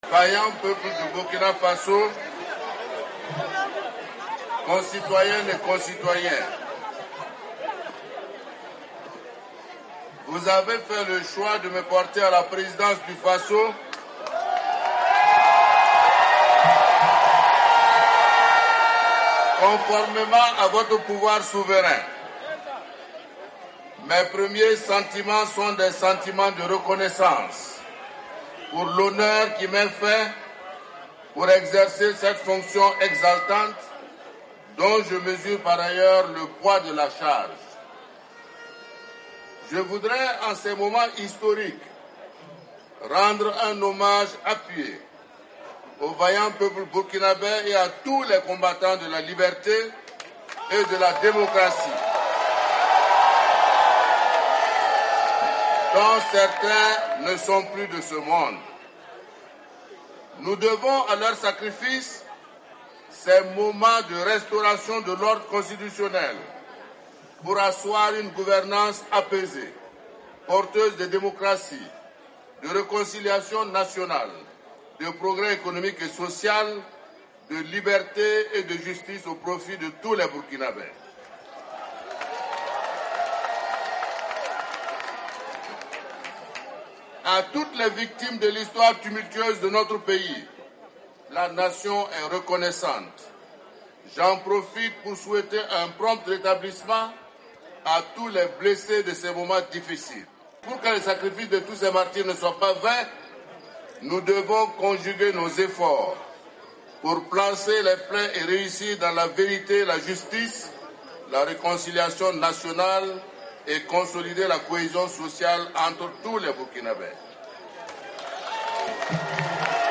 Acclamé par plusieurs milliers de personnes réunies devant le siège de son parti, M. Kaboré a déclaré à ses partisans : "Nous devons nous mettre au travail immédiatement. C'est tous ensemble que nous devons servir le pays."
Discours de Roch Marc Christian Kaboré après l'annonce de sa victoire